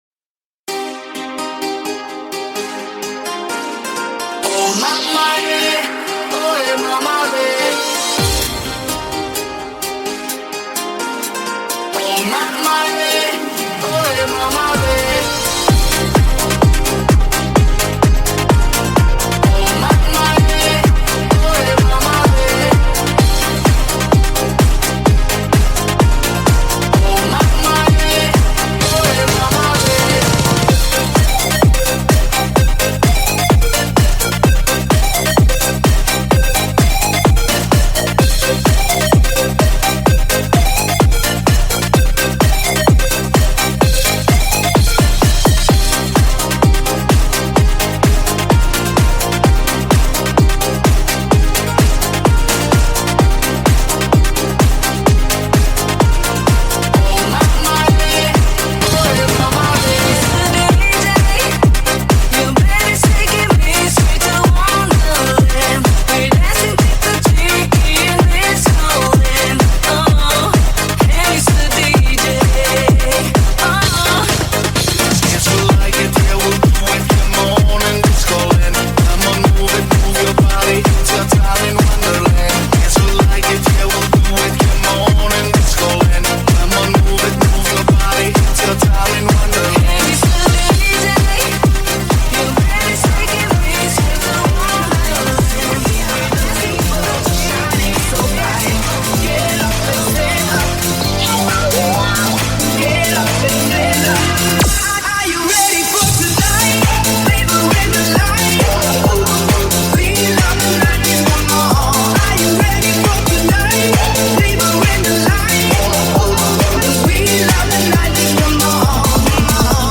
Euro Mix Dance
Euro-Mix-Dance.mp3